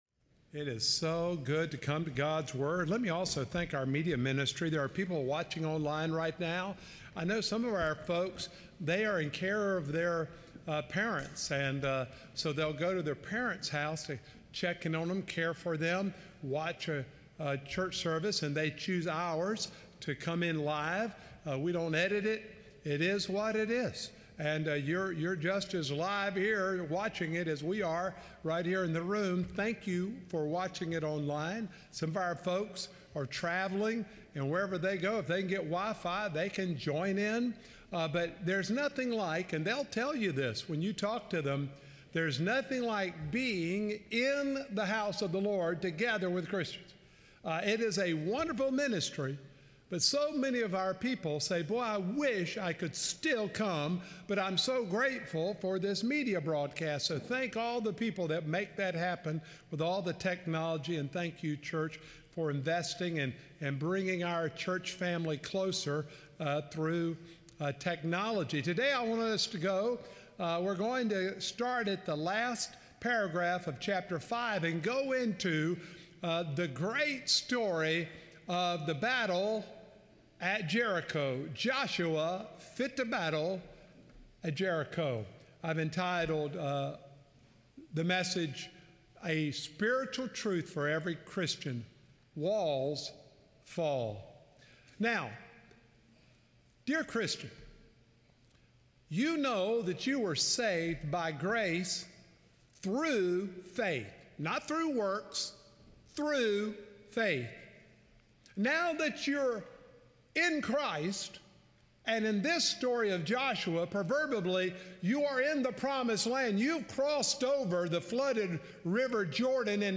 November-9-2025-Sermon-Audio.m4a